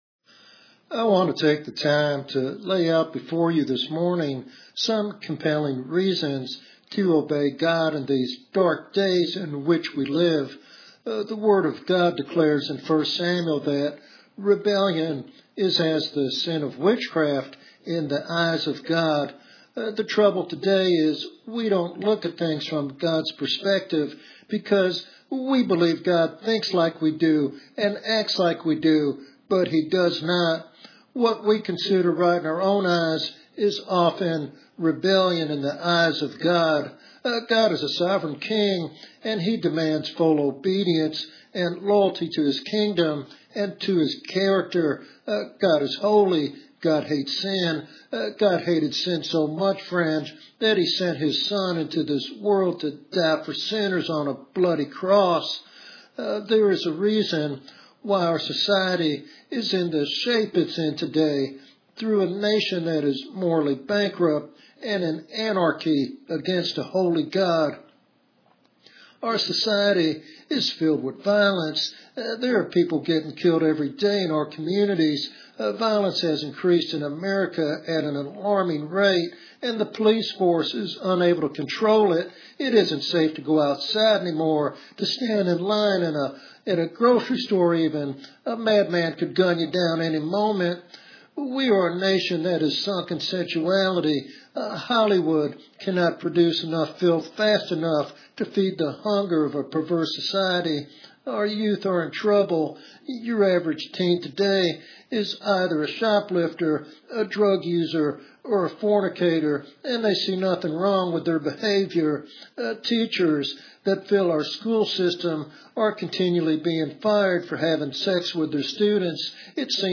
In this powerful expository sermon